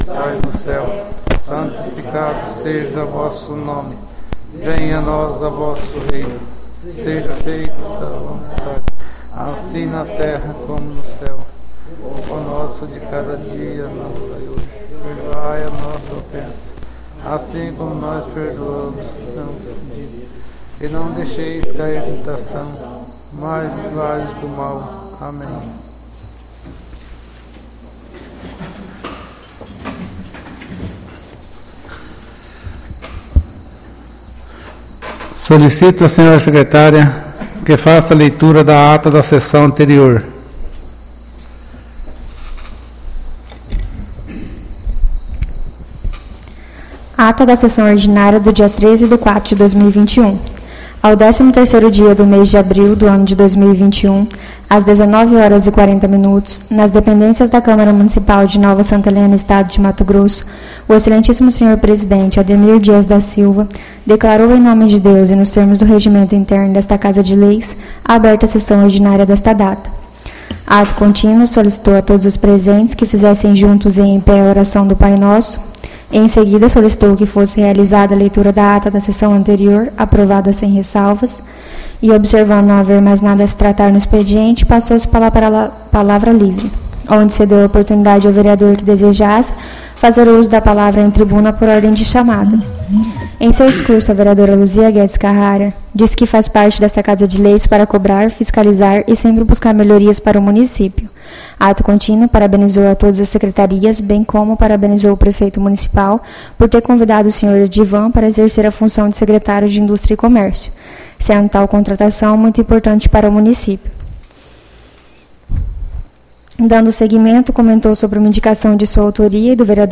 ÁUDIO SESSÃO 20-04-21